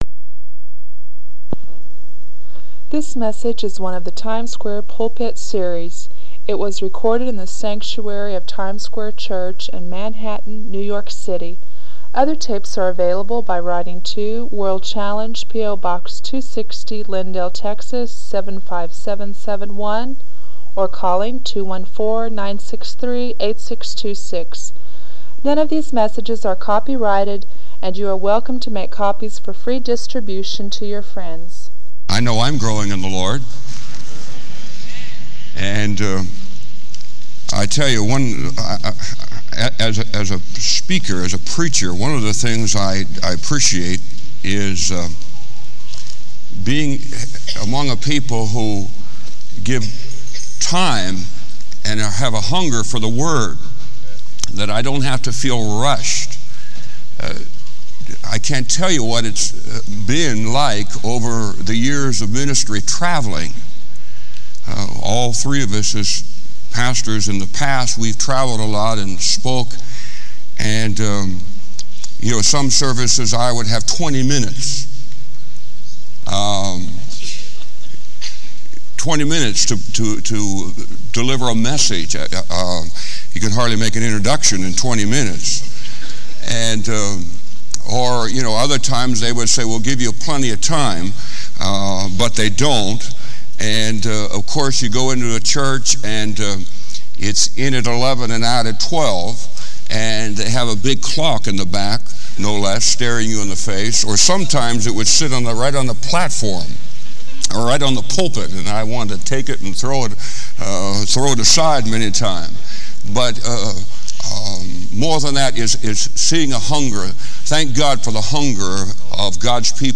This sermon encourages listeners to reflect on their own attitudes toward ministry and God's work.